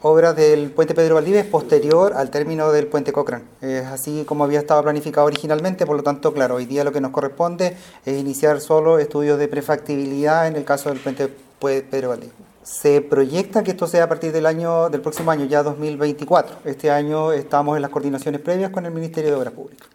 El seremi de Vivienda en Los Ríos, Daniel Barrientos, confirmó la situación y dijo que recién están analizando estudios de prefactibilidad.